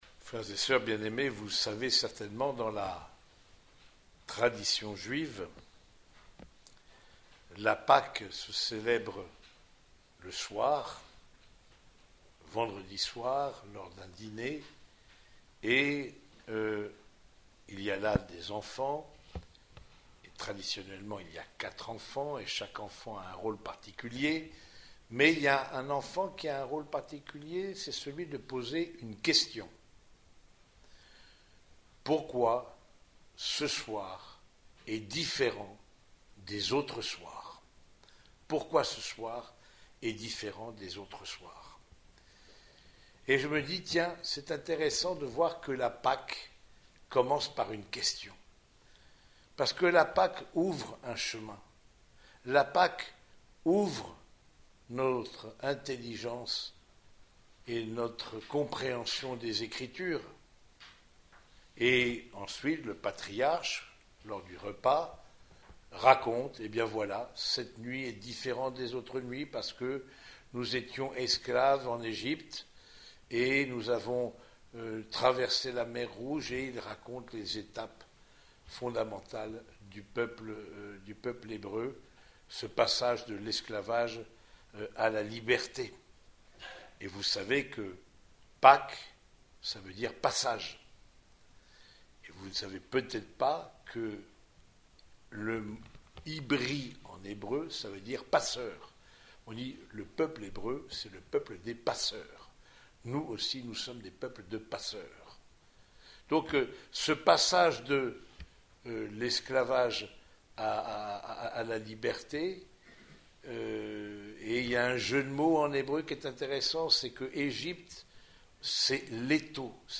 Homélie du troisième dimanche de Pâques
Cette homélie a été prononcée au cours de la messe dominicale célébrée dans la chapelle des sœurs franciscaines de Compiègne.